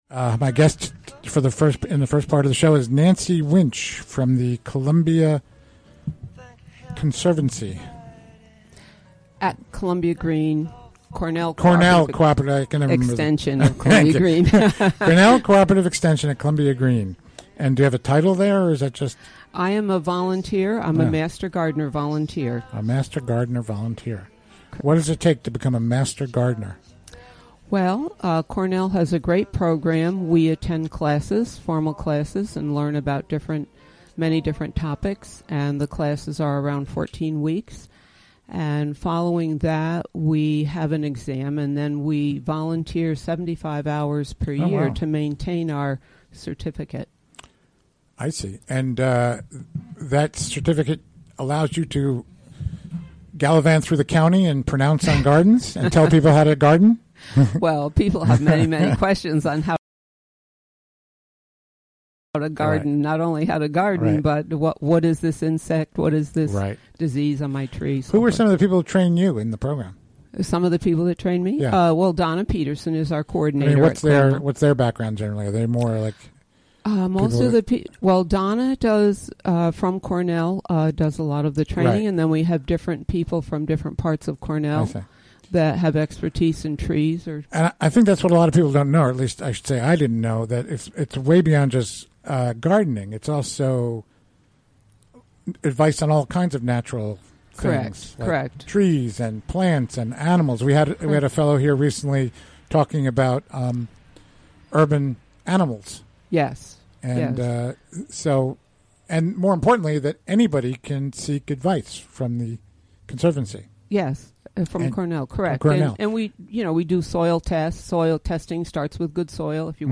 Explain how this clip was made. Recorded live during the WGXC Afternoon Show Thursday, March 4, 2017.